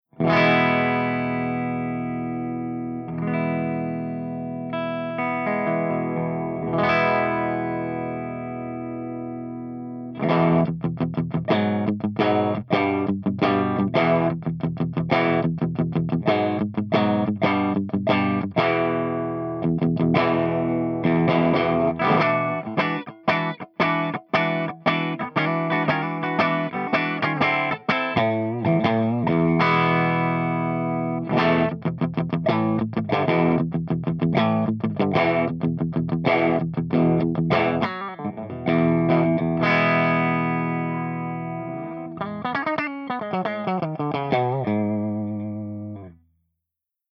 146_MESA SINGLE RECTIFIER_CH1CLEAN_V30_P90